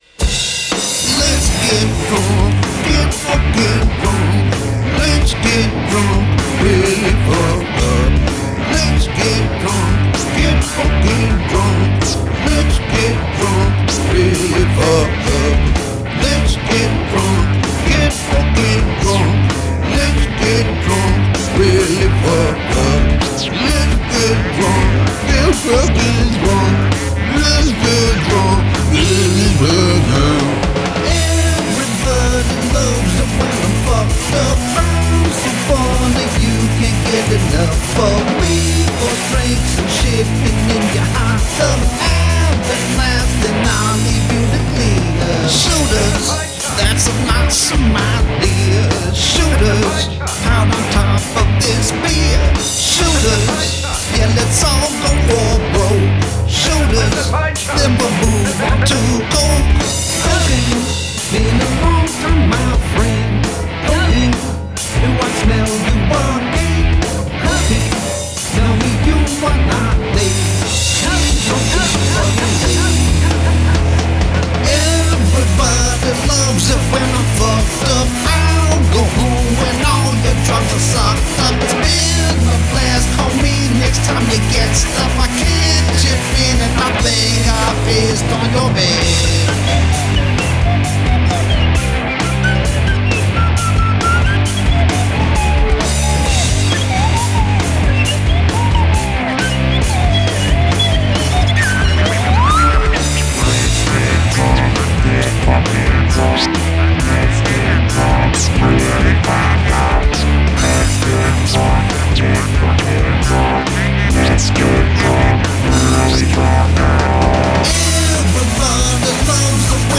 Don’t expect polish.
It’s loud.